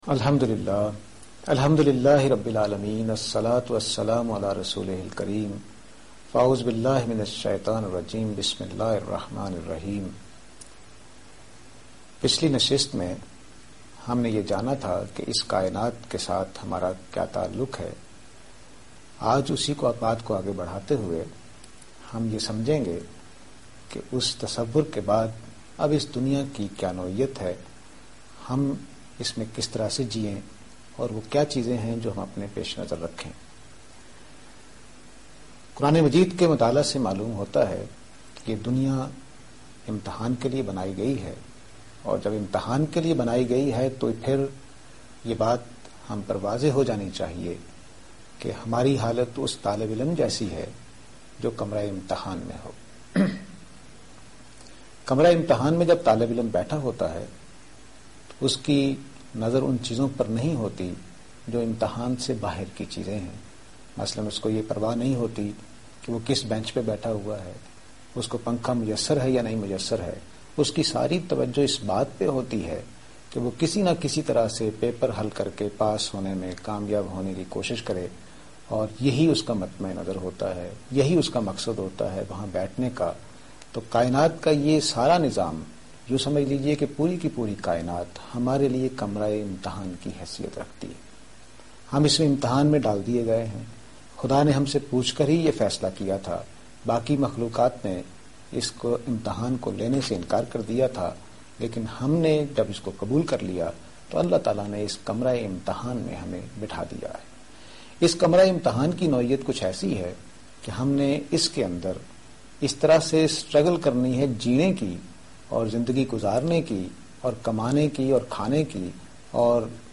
Program Tazkiya-e-Ikhlaq on Aaj Tv.